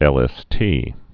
(ĕlĕs-tē)